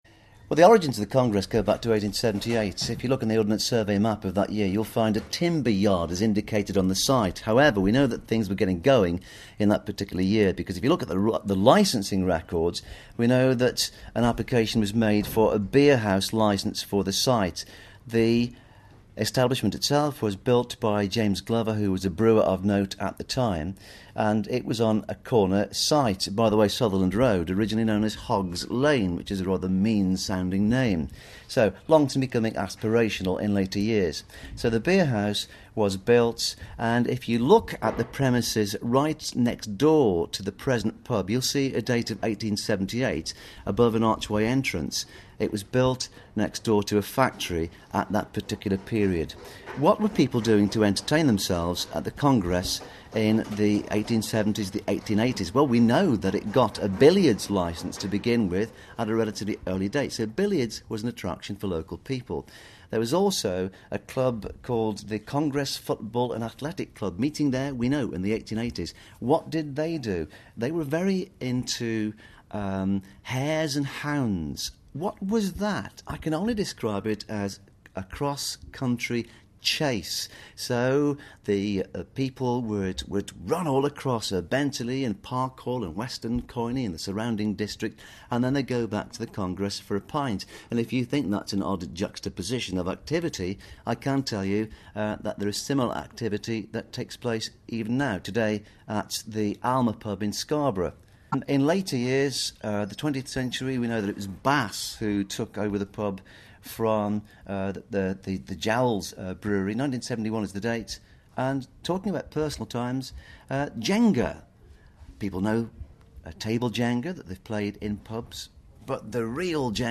Remembered by local historian